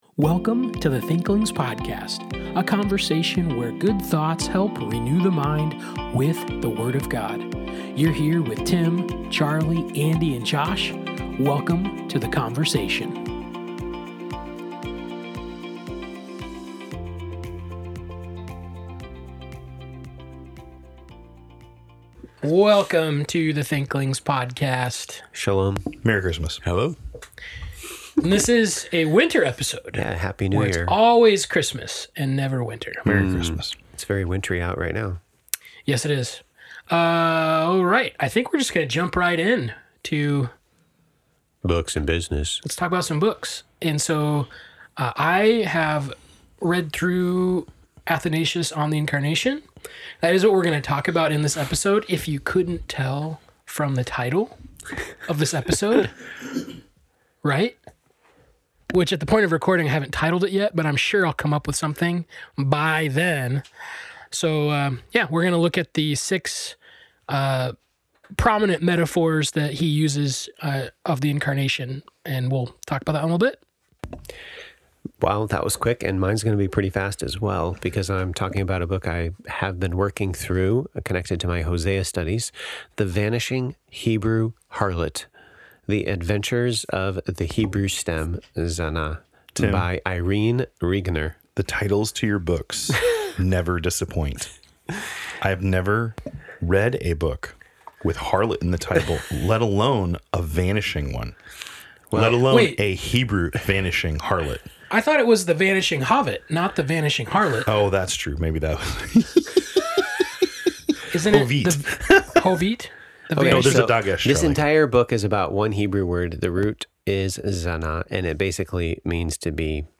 Thanks for tuning in to this week’s conversation!